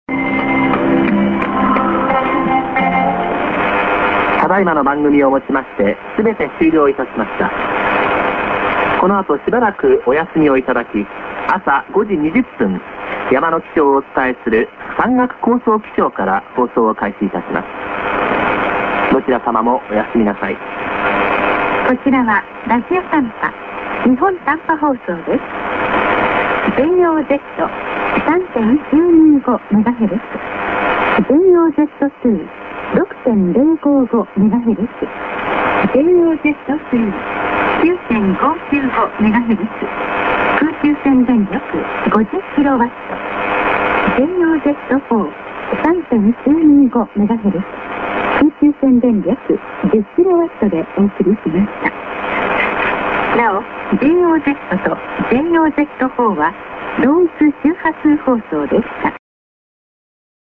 End ANN(men)->ID+SKJ(women)